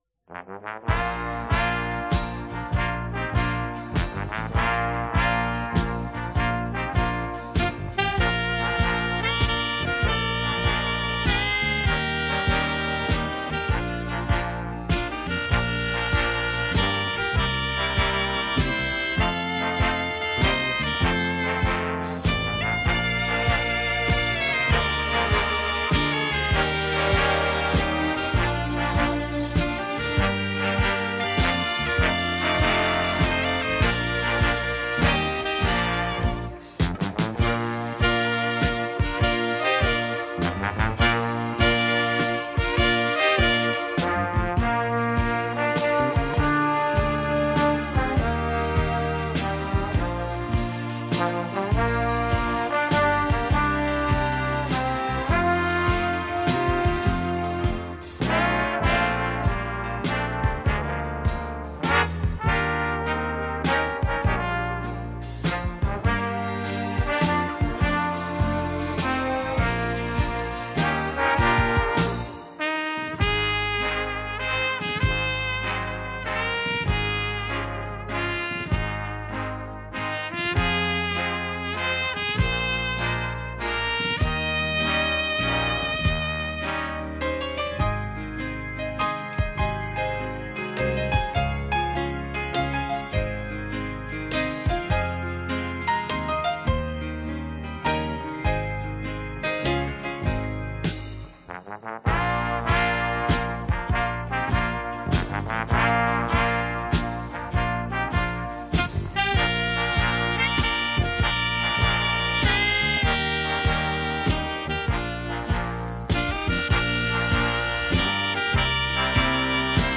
Жанр: Pop, Classical, Easy Listening